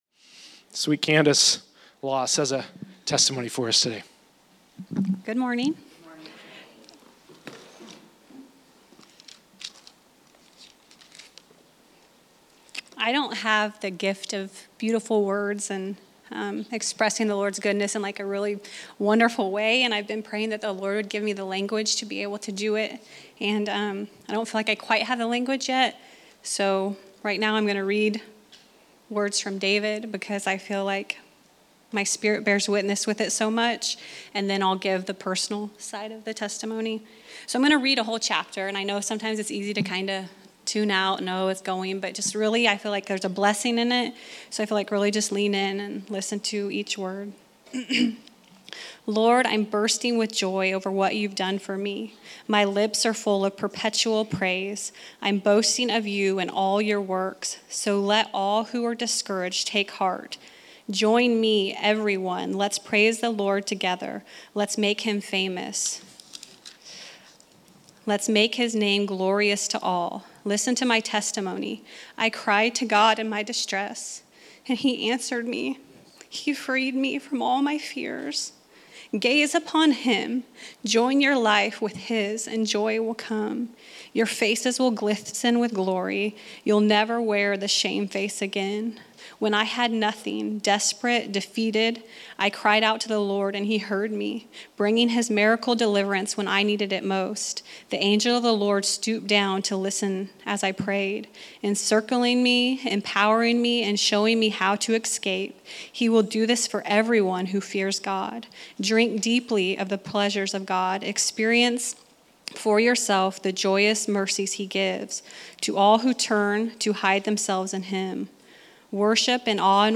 Healing & Deliverance Testimony